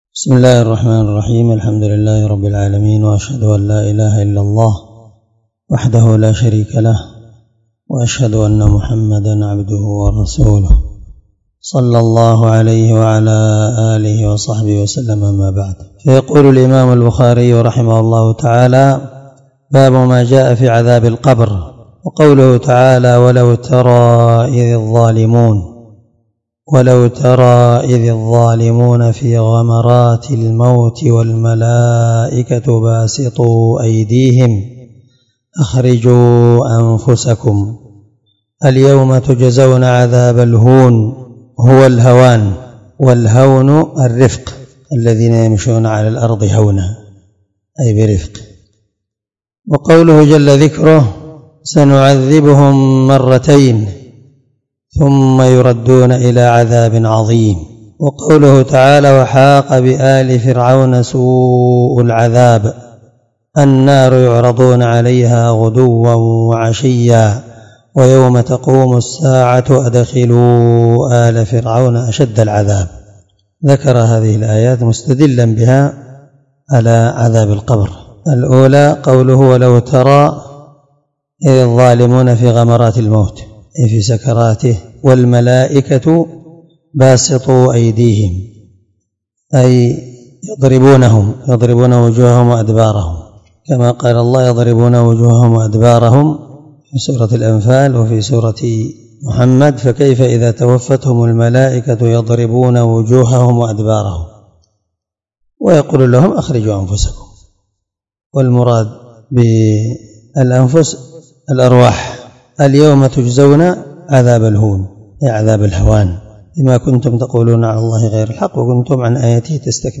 789الدرس 62من شرح كتاب الجنائز حديث رقم(1369 )من صحيح البخاري
دار الحديث- المَحاوِلة- الصبيحة.